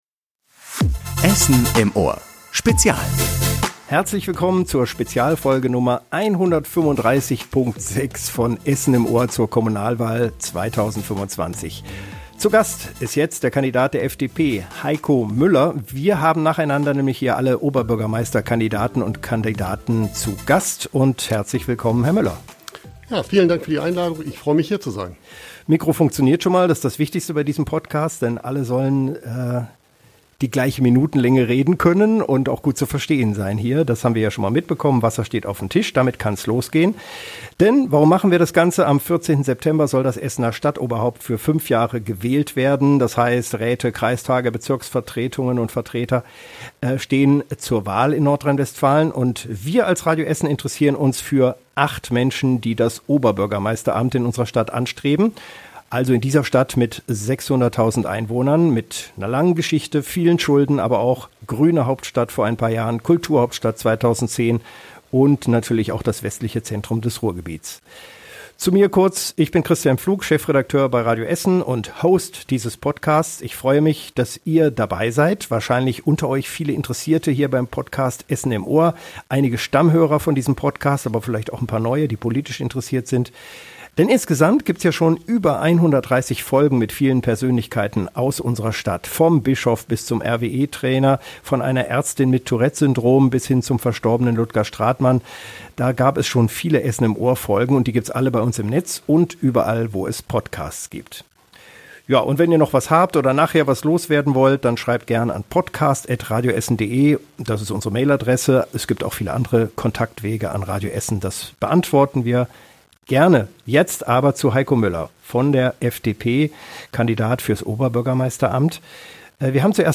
~ Essen im Ohr - Der Talk mit Persönlichkeiten aus der Stadt Podcast